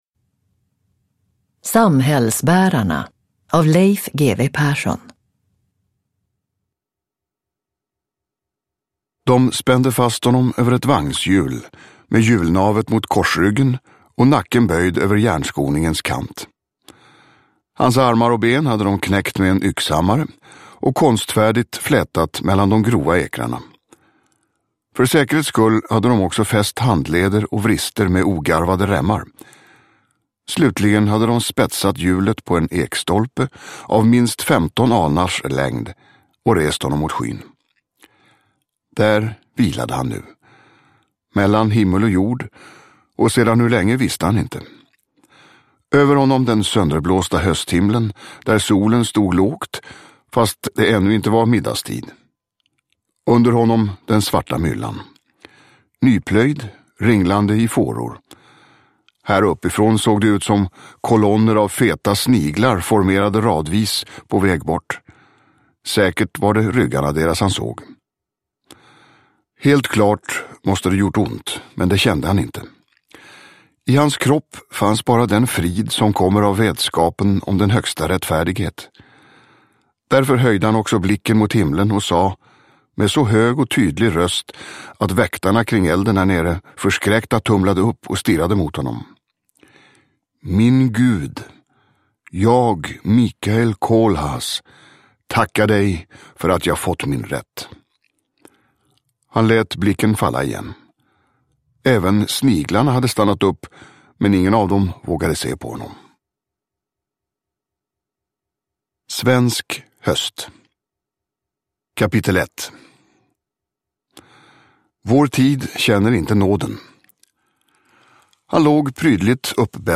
Samhällsbärarna – Ljudbok – Laddas ner
Uppläsare: Tomas Bolme